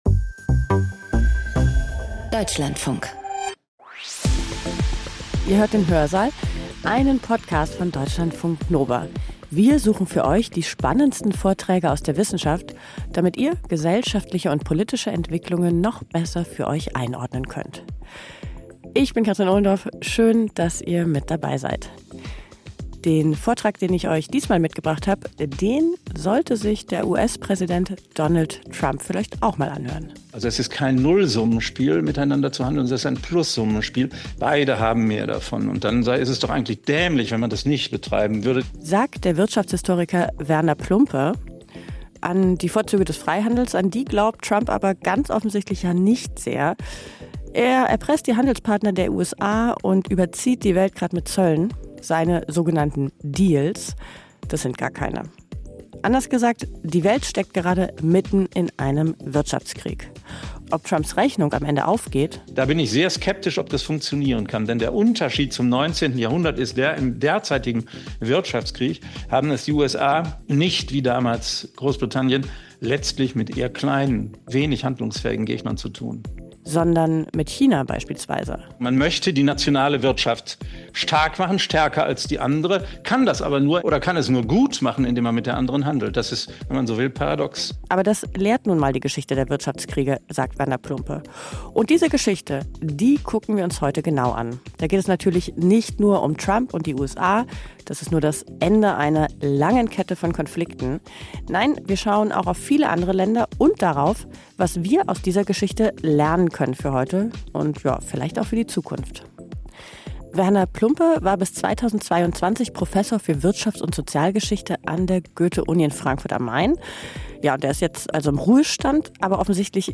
Seinen Vortrag hat er in der Reihe vhs.wissen live gehalten, einem Gemeinschaftsprojekt von rund 300 Volkshochschulen in Deutschland, Österreich, der Schweiz und in Italien.
Vorträge